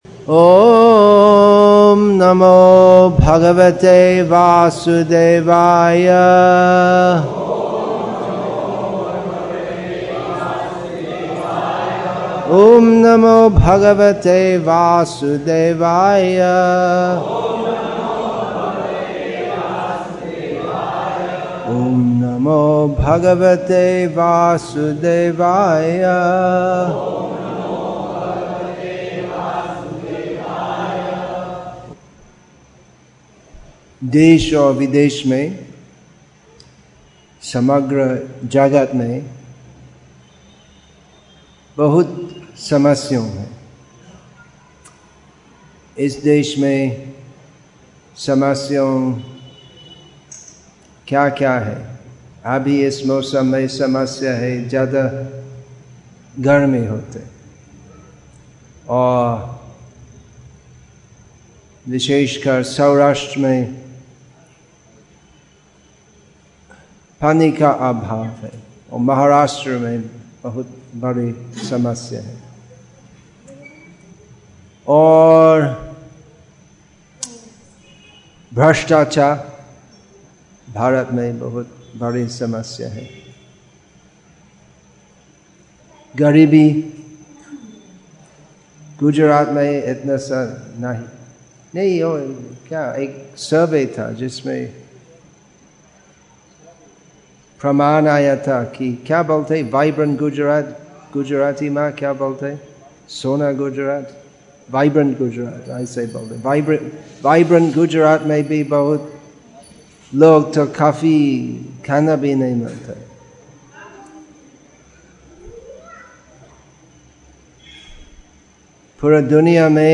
More Serious than Huge Floods June 23, 2013 Preaching , Sādhana-bhakti , Social Issues English with தமிழ் (Tamil) Translation; Salem, Tamil Nadu , India 39 m 18.89 MB Download Play Add To Playlist Download